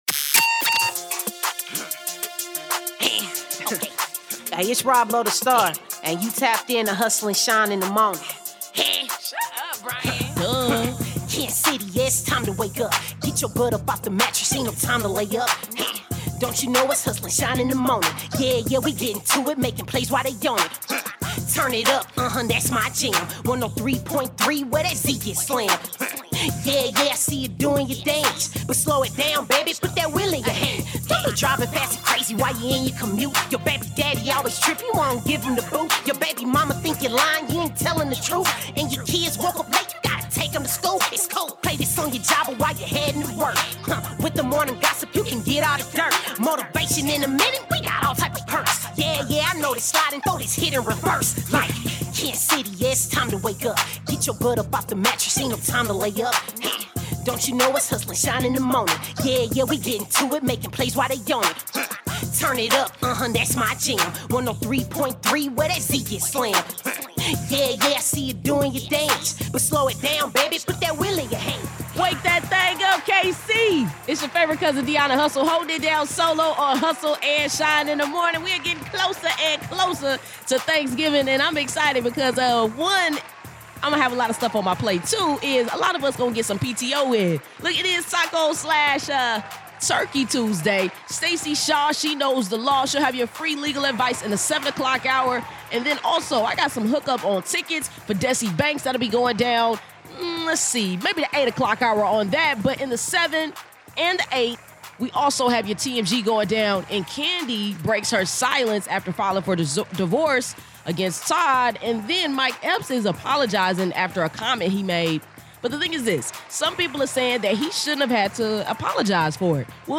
BUT SAME SAY HE SHOULDNT HAVE TO Interview with Signature Segments featuring Motivational Minute